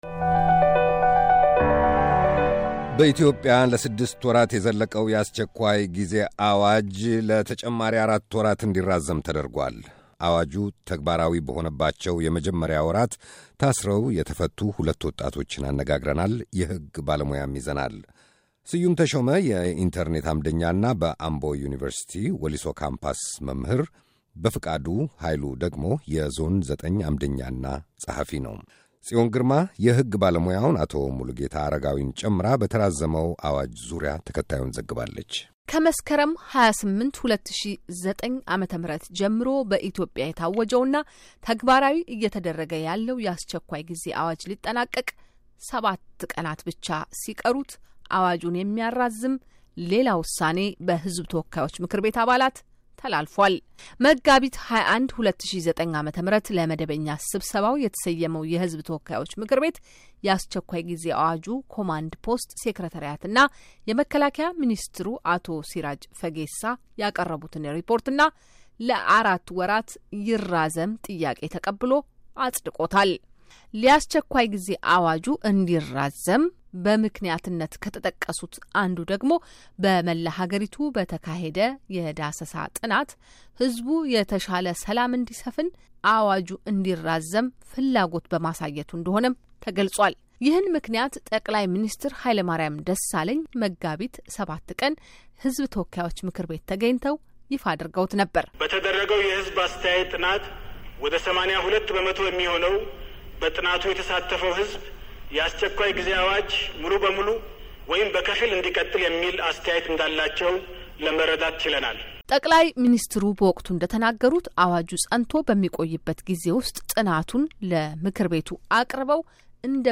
በኢትዮጵያ ከዚህ ቀደም ታውጆ የነበረውና ለስድስት ወራት የዘለቀው የአስቸኳይ ጊዜ ዐዋጅ ለአራት ወራት ተራዝሟል። የመጀመሪያው ዐዋጅ ተግባራዊ መደረግ በጀመረበት የመጀመሪያ ወራት ታስረው የተፈቱ ሁለት ወጣቶችና የሕግ ባለሞያ አነጋግረናል።